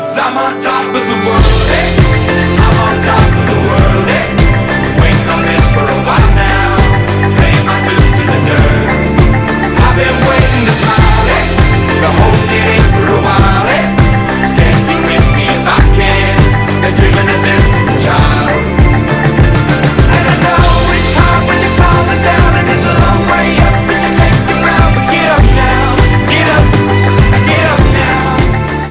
• Качество: 192, Stereo
мотивирующие
веселые
alternative
indie rock